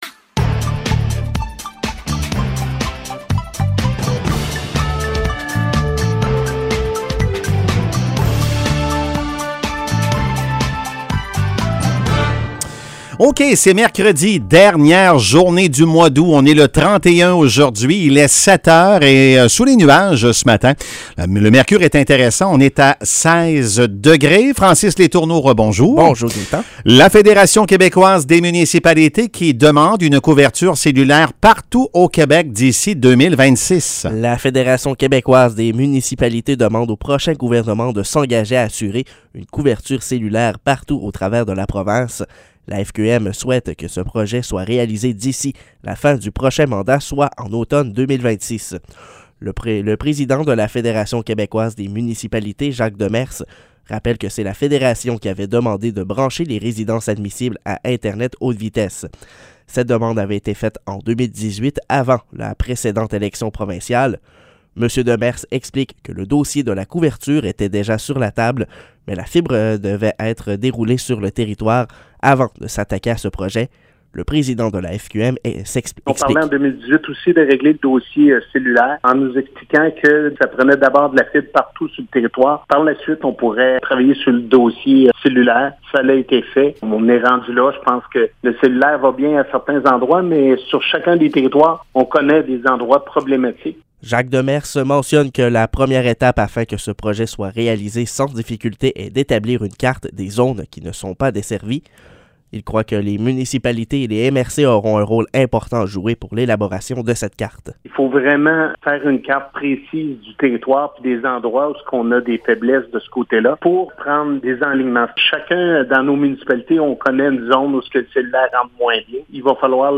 Nouvelles locales - 31 août 2022 - 7 h